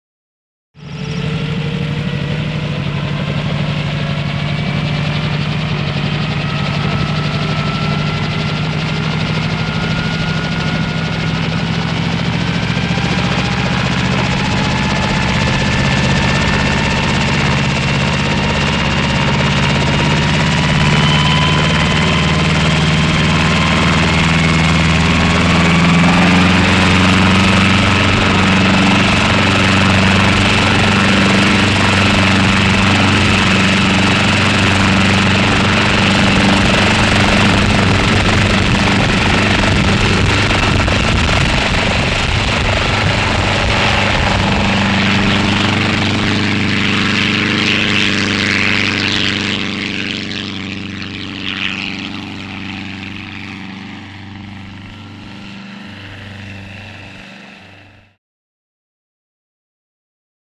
Helicopter; Start / Take Off; Brantly Helicopter Start Up After Two Attempts Motor Idling, Then Takes Off And Away.